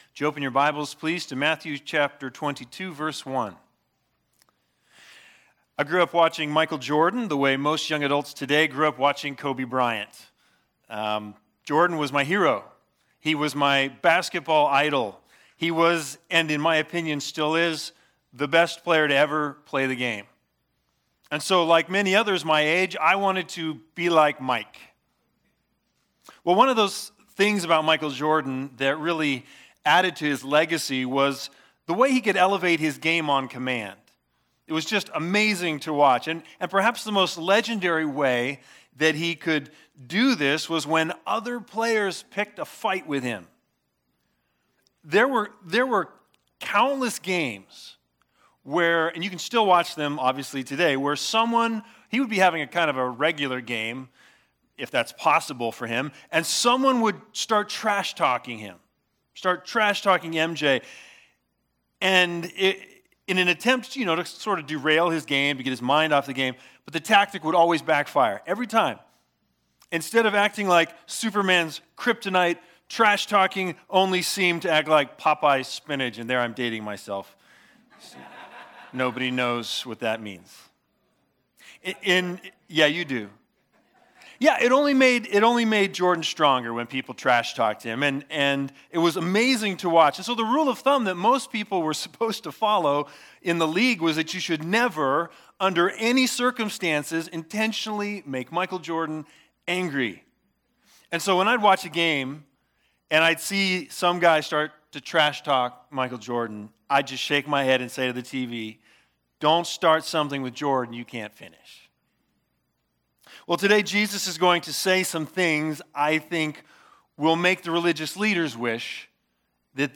Sunday Sermons The Big Idea